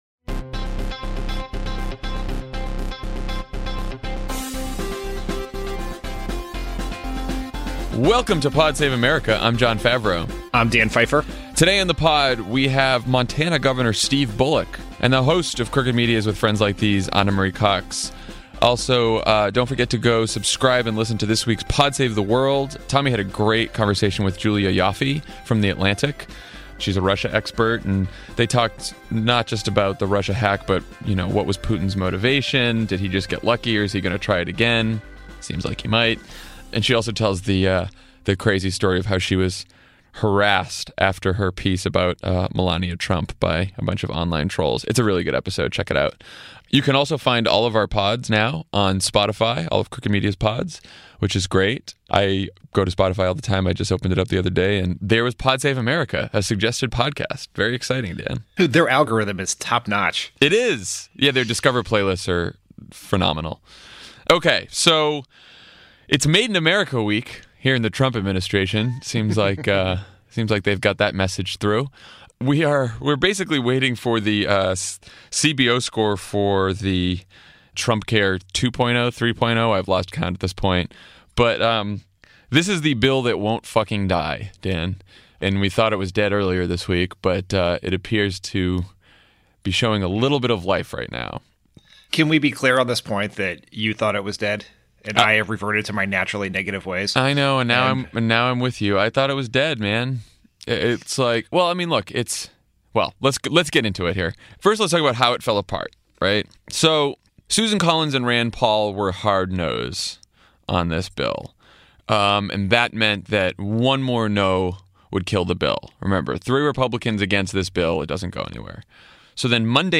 Trumpcare is dead and alive and dead and alive again, and we discover from a terrifying New York Times interview that the President doesn’t actually know what health insurance is. Then Jon and Dan talk to Montana Governor Steve Bullock about his vision for the party and the country, and Ana Marie Cox joins to talk about Trump’s elimination of teen pregnancy prevention programs.